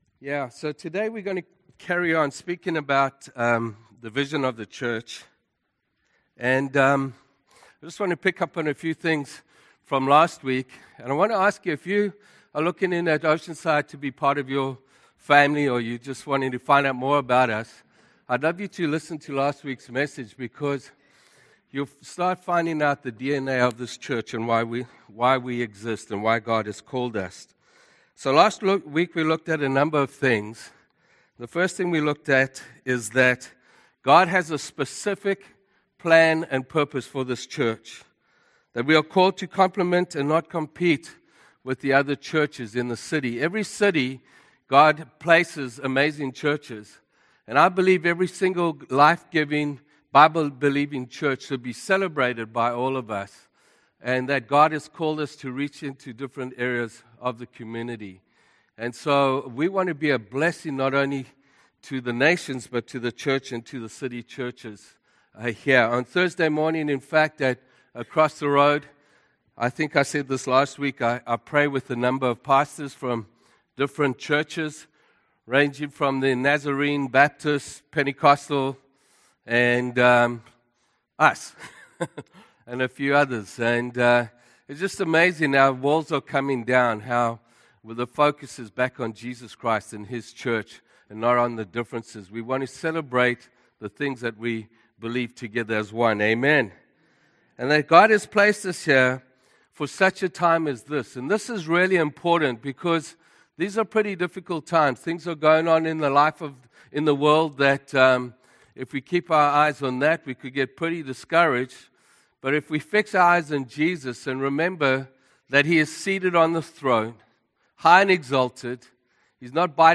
Sermons - Fulfilling Our God Given Vision - 2 - Apr 19, 2015